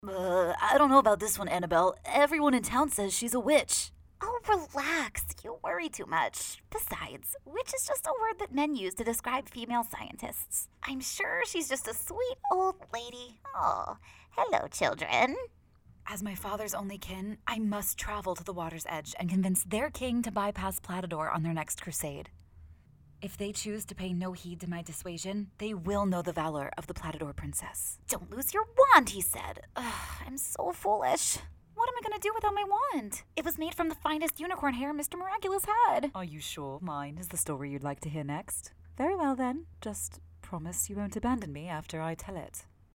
TV & Voice Demos
CHARACTER READINGS